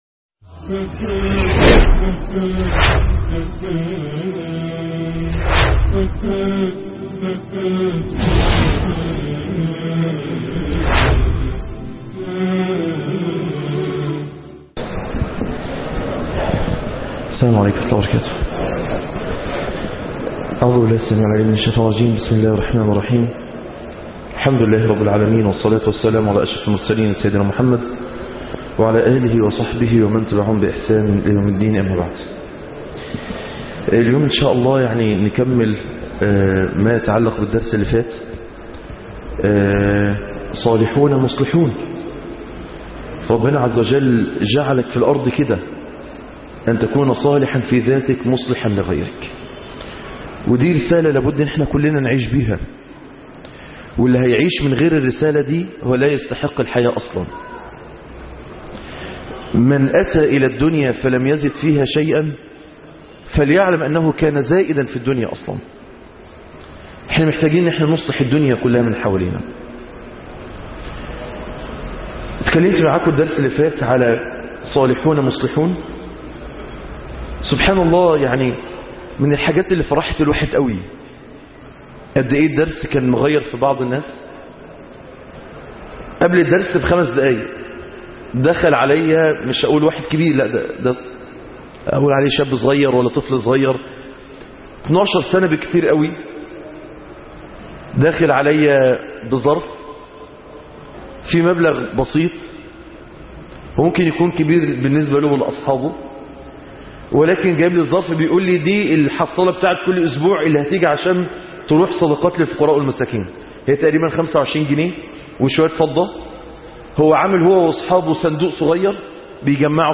دروس مسجد الصديق بالمنصورة